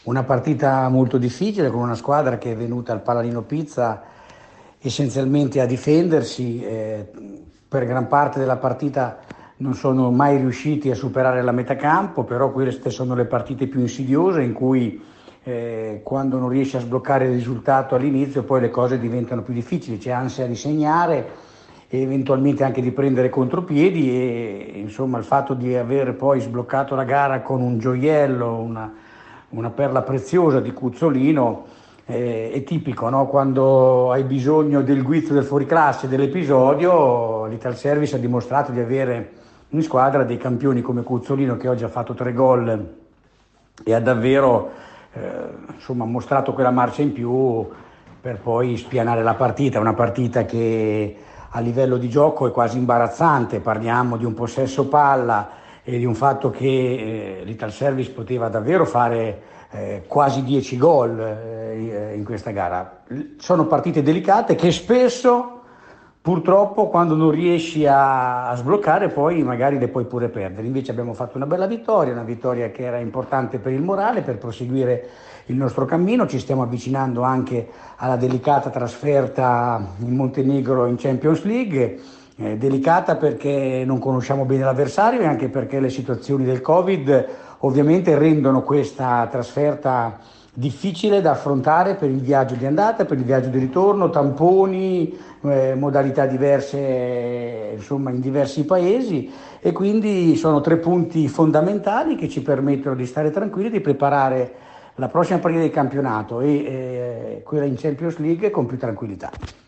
E’ una Italservice Pesaro di nuovo vincente quella ammirata al Pala Nino Pizza, contro la CDM Futsal Genova. 4 a 1 il risultato finale. La nostra intervista